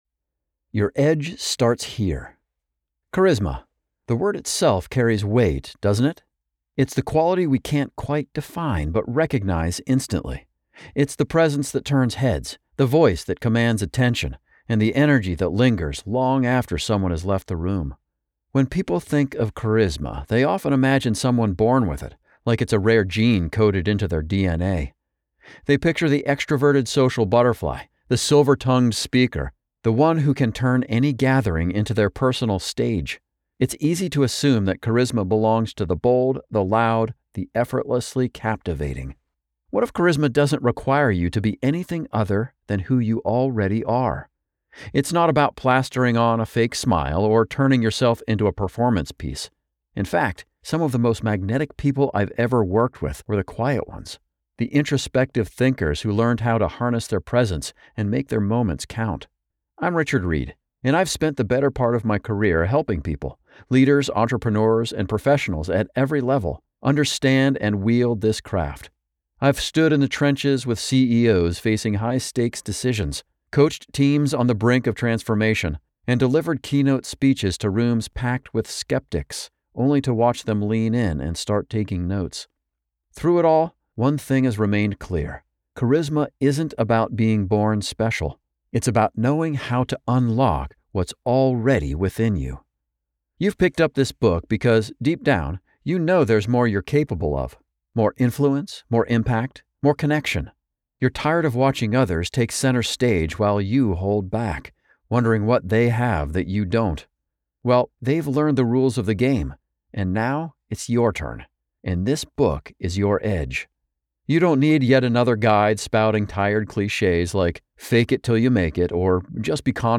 Perfect for busy professionals, this audiobook allows you to master authentic charisma while on the move—whether commuting, exercising, or managing your day-to-day tasks.